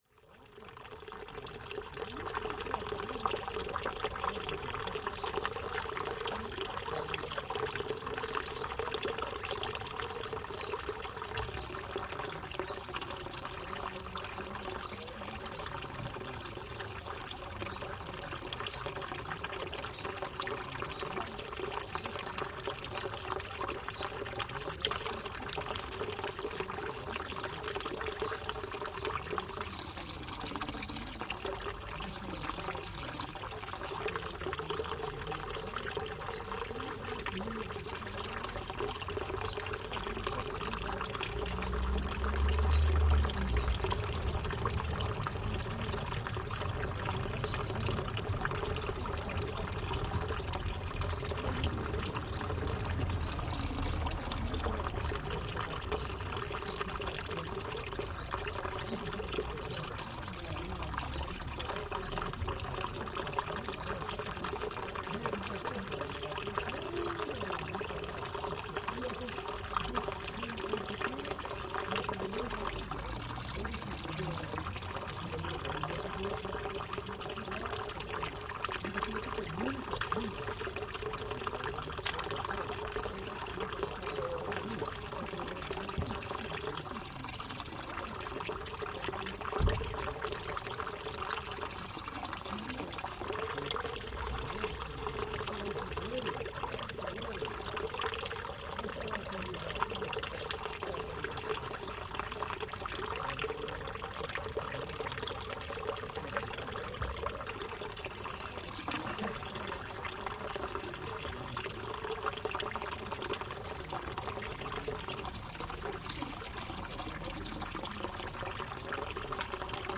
Uma gravação do fluxo da água na famosa fonte das três bicas existente na zona do Largo da Sé. Gravado com Edirol R44 e um par de microfones de lapela Audio-Technica AT899.
Tipo de Prática: Paisagem Sonora Rural
Viseu-Rua-do-Adro-Fonte-das-Três-Bicas.mp3